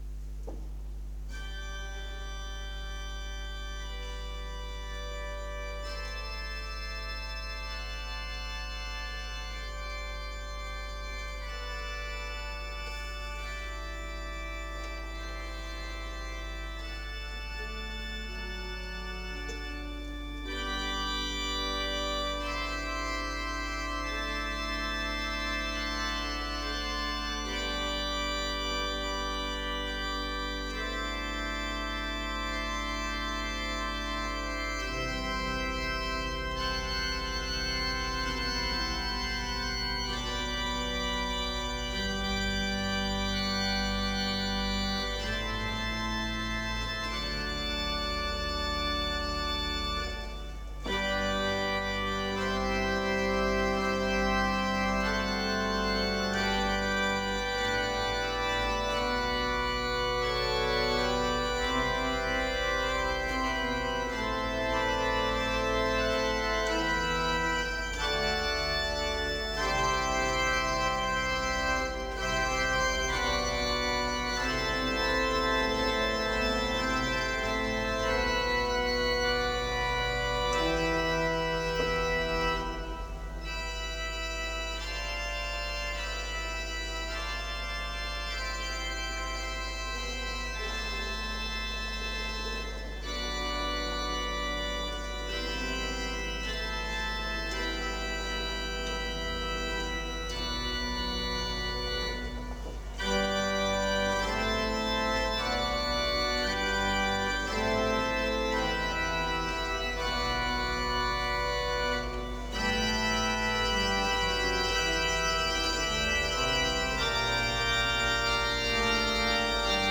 Chiesa parrocchiale di S. Giorgio in S. Pietro al Po
ASCOLTA LA VOCE DEL LINGIARDI-ORCHESTRA DI CREMONA
[attendere il caricamento; registrazione amatoriale effettuata con minidisc]
Solo di Flutta
Ripieni